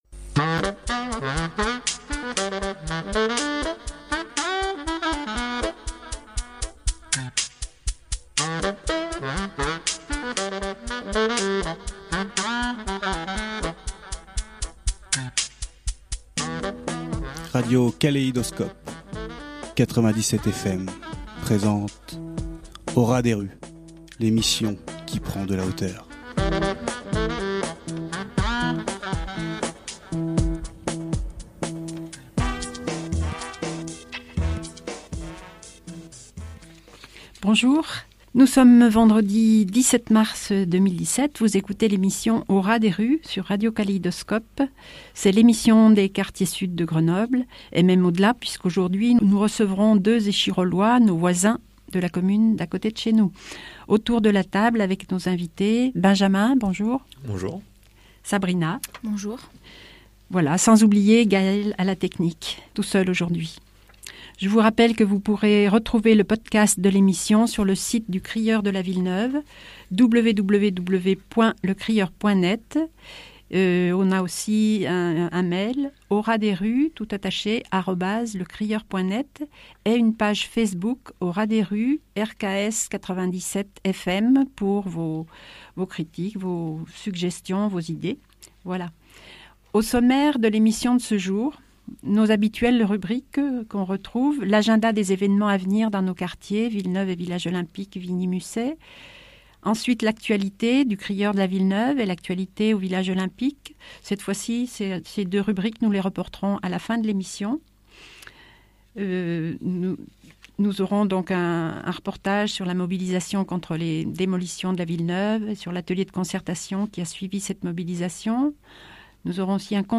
Au ras des rues est une émission bimensuelle sur Radio Kaléidoscope (97 fm) qui s’intéresse à l’actualité des quartiers sud de Grenoble et de l’agglo : Villeneuve, Village Olympique, Mistral, Abbaye-Jouhaux, Ville Neuve d’Échirolles…